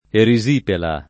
vai all'elenco alfabetico delle voci ingrandisci il carattere 100% rimpicciolisci il carattere stampa invia tramite posta elettronica codividi su Facebook erisipela [ eri @& pela ] (pop. resipola o risipola ) s. f. (med.)